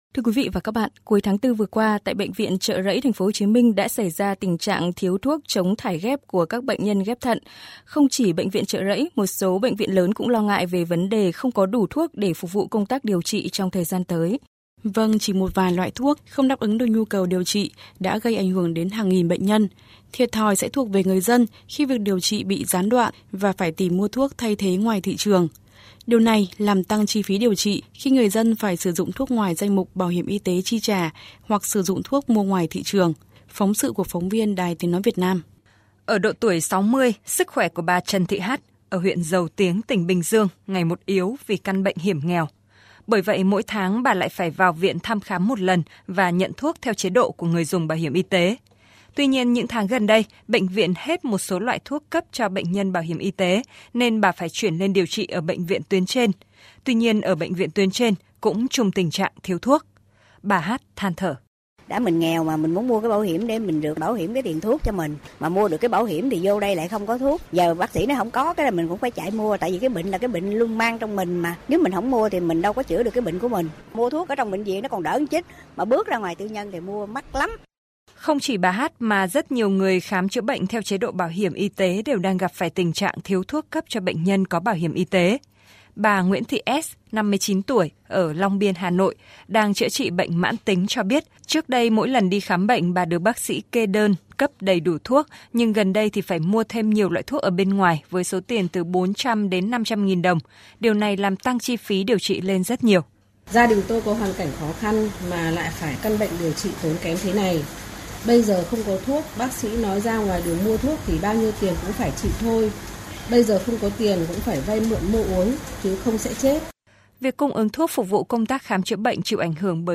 Phóng sự của phóng viên Đài TNVN